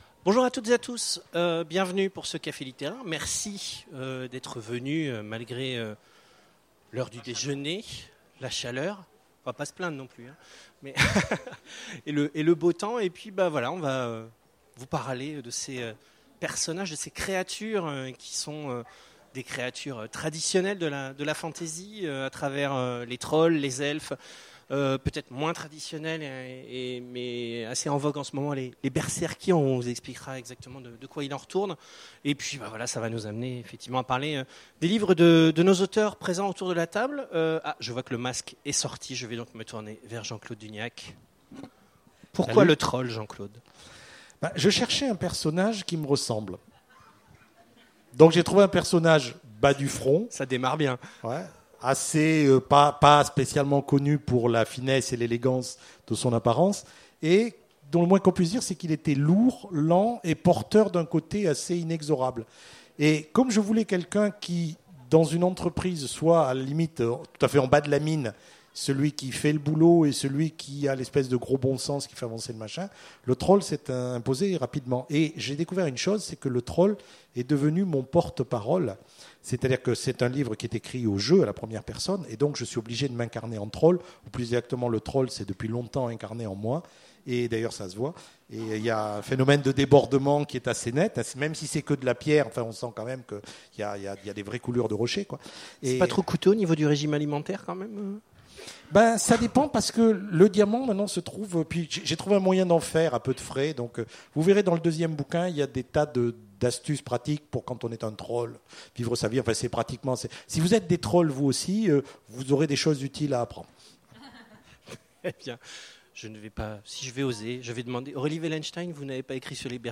Imaginales 2016 : Conférence Trolls, elfes, berserkers…